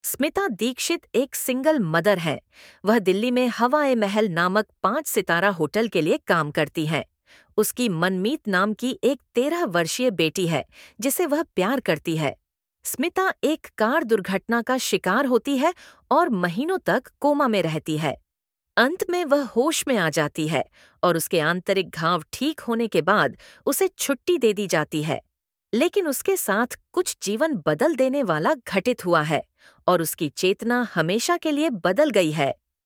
PLAY VOICE SAMPLE